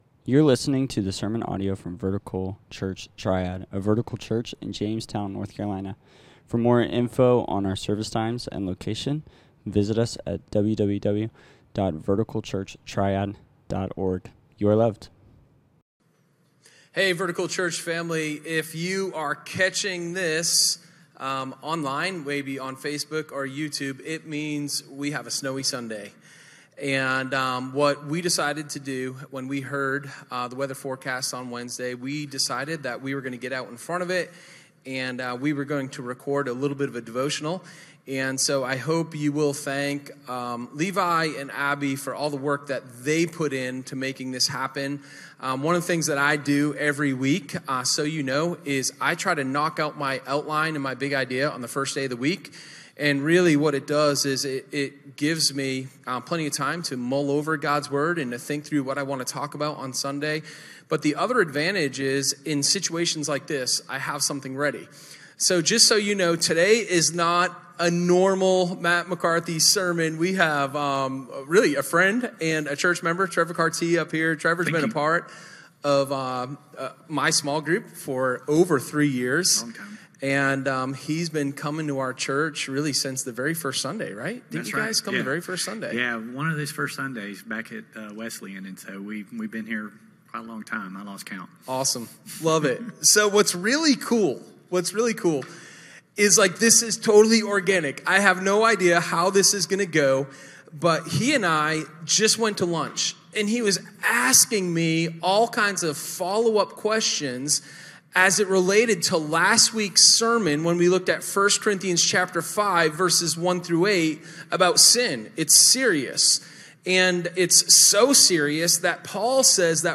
Sermon01_16_Christians_and_Culture.m4a